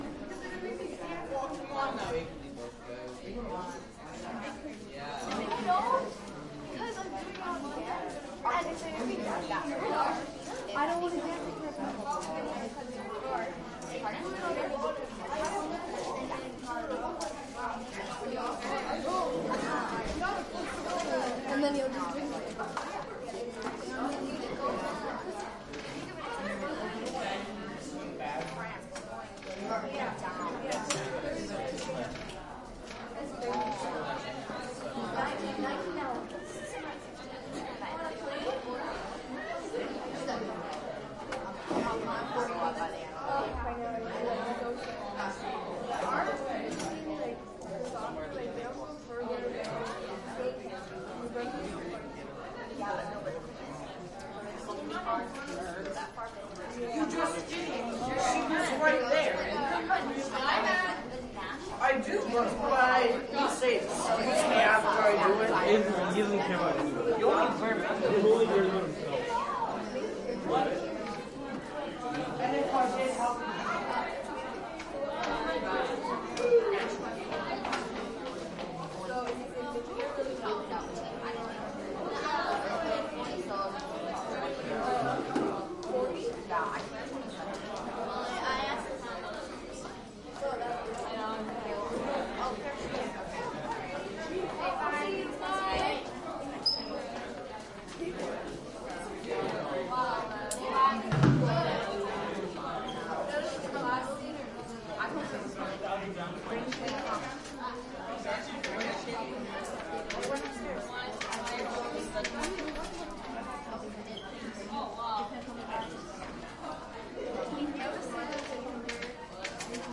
高中" 人群中的高中 大走廊 中等人群 活跃的运动经过
描述：人群int高中大走廊中等主动运动路过
Tag: INT 走廊 人群 媒体 学校 大型